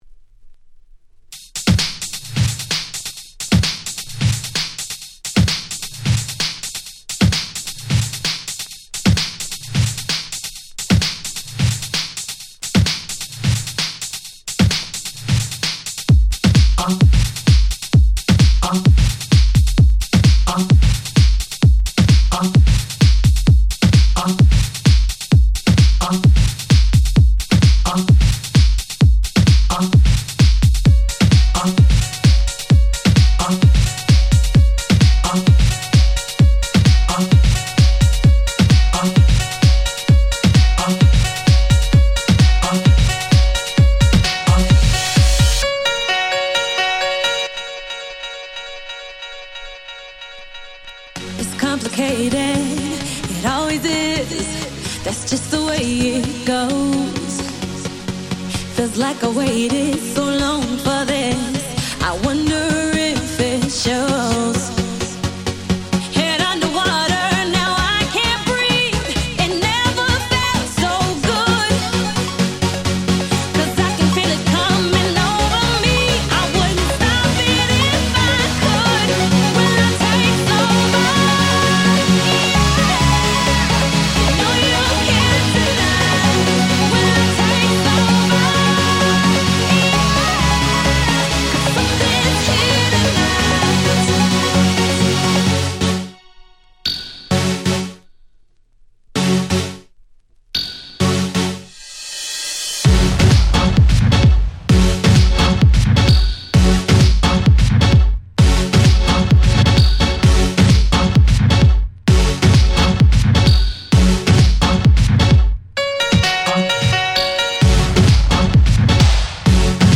09' Super Hit EDM !!